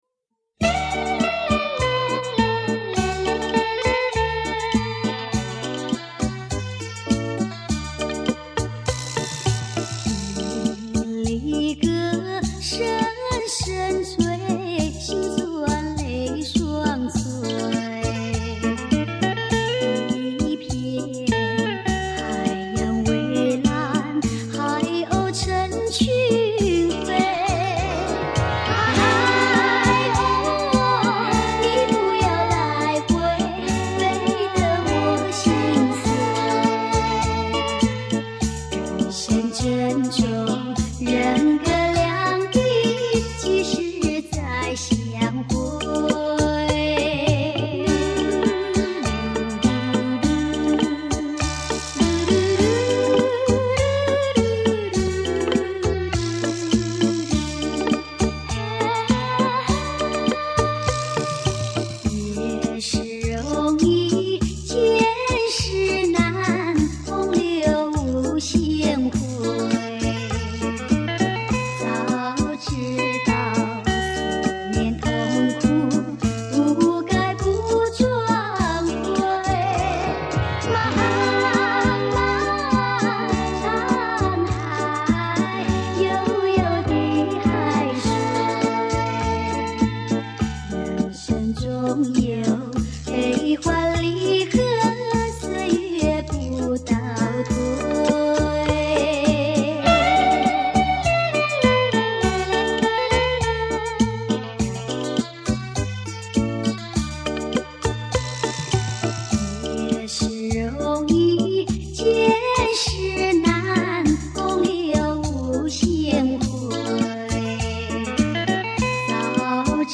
磁带转APE
注意：由于磁带年老，效果打折，非喜勿下